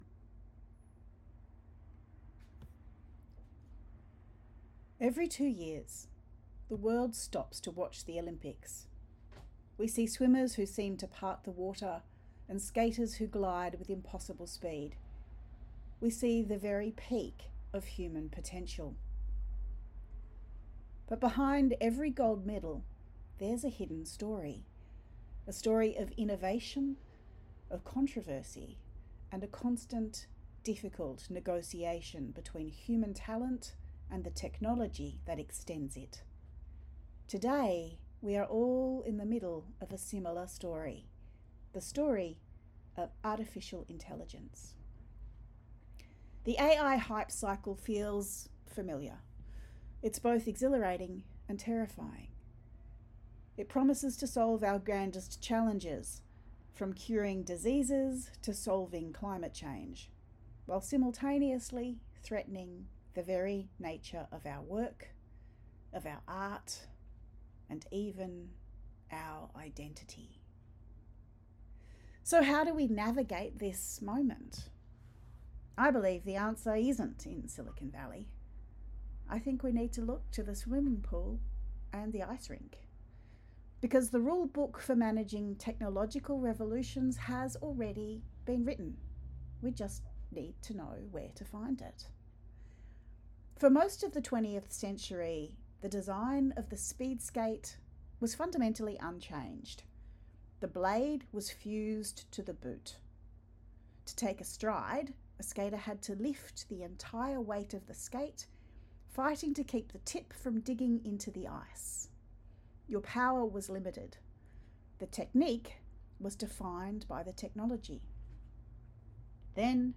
The article was created by GenAI, prompted, edited and spoken by me.